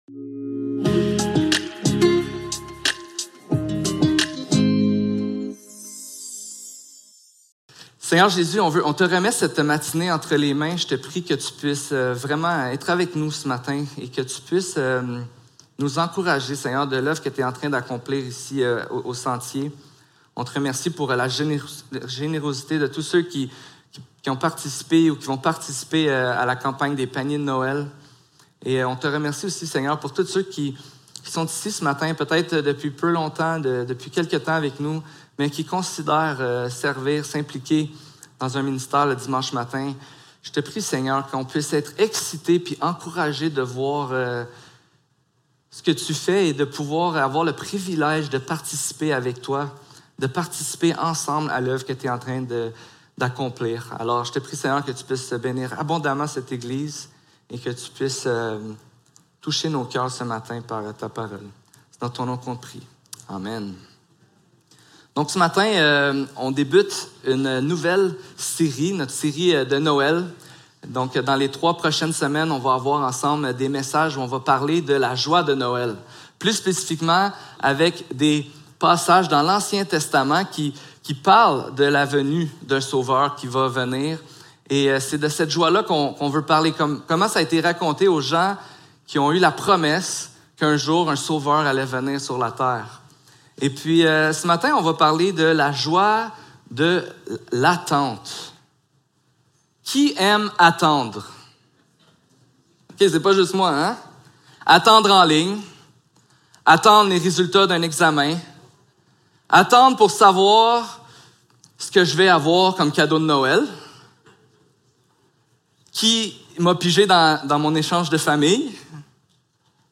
Ésaïe 12 Service Type: Célébration dimanche matin Description